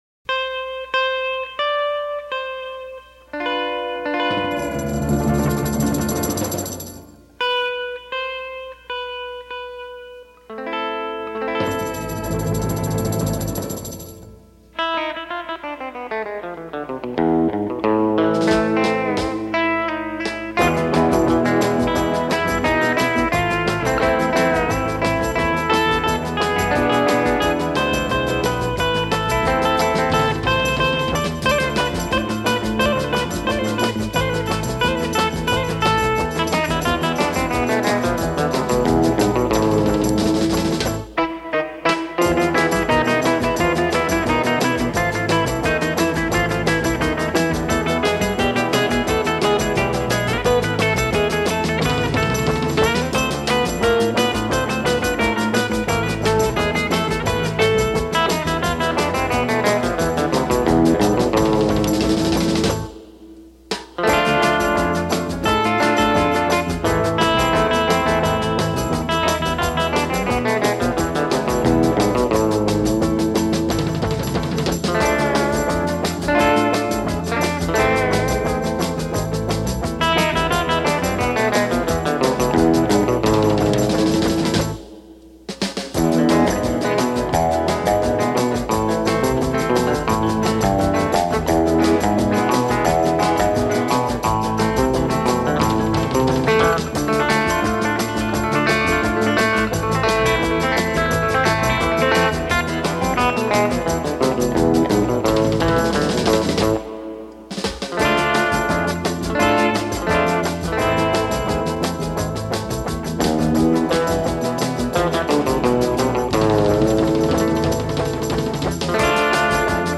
Surf